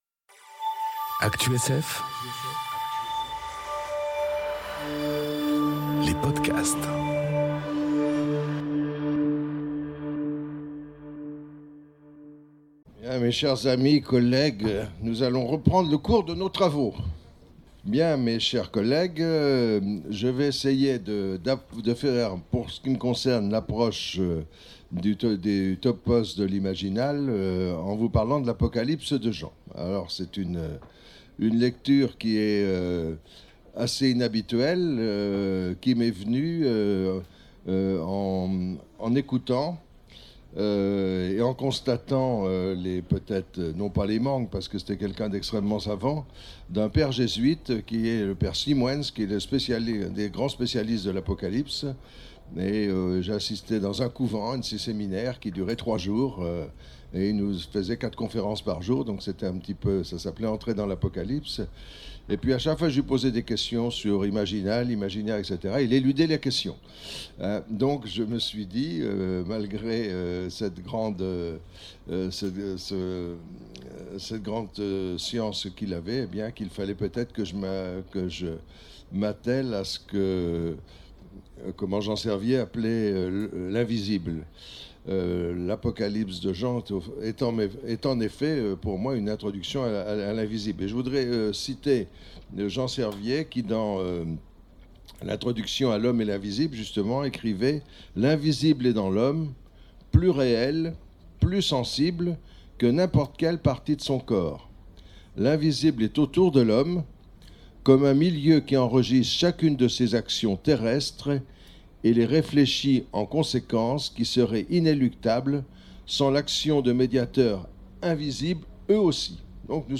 Imaginales 2019 : Colloque, L'Apocalypse de Jean